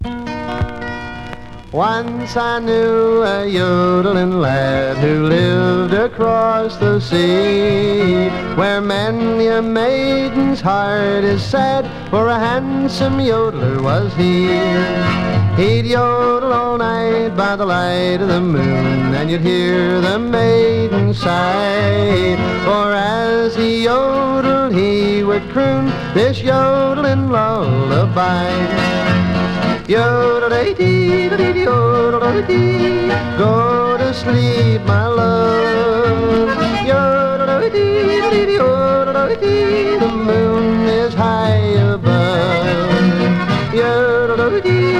Country, Hillbilly　UK　12inchレコード　33rpm　Mono